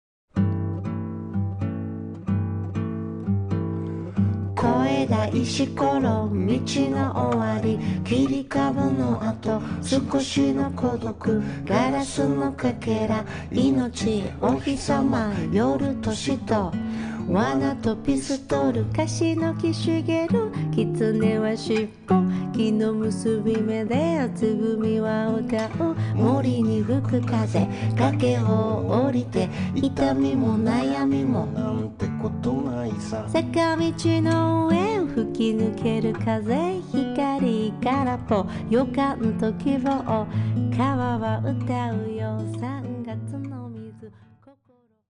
guitar / vocal
bass
piano / rhodes / melodica